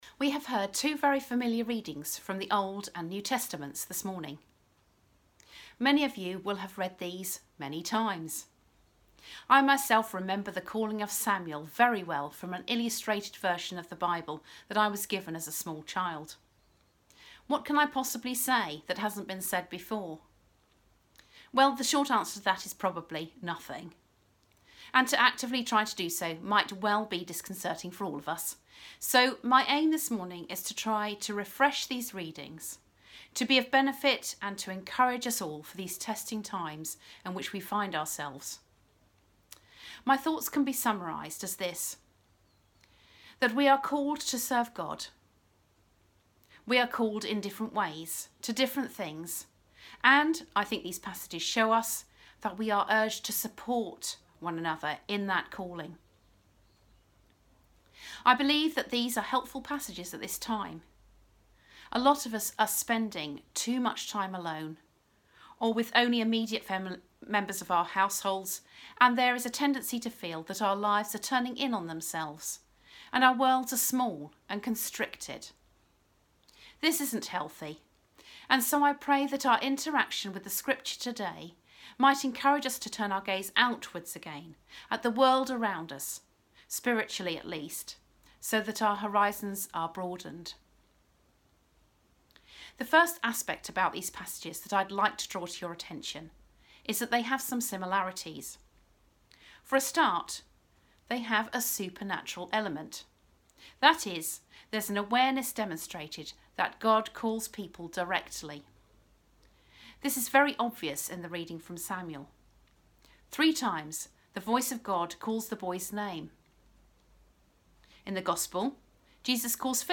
latestsermon-2.mp3